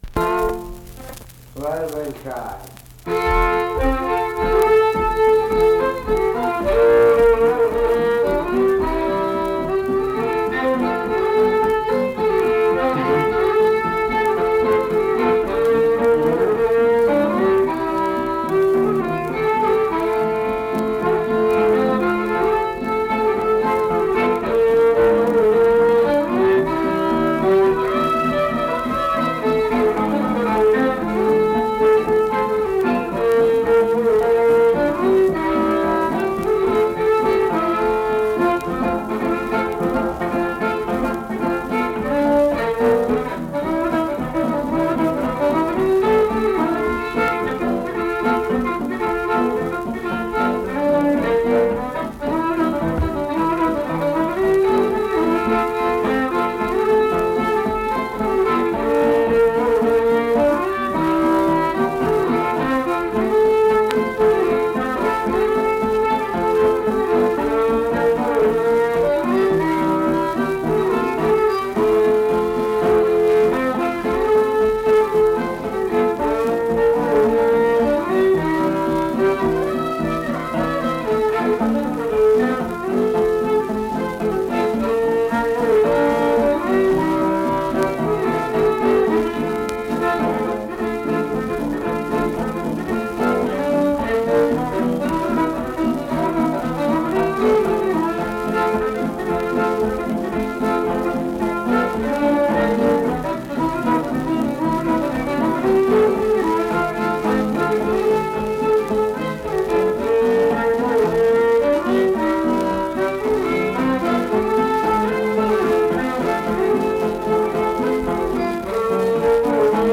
Accompanied guitar and unaccompanied fiddle music performance
Verse-refrain 4(2).
Instrumental Music
Fiddle, Guitar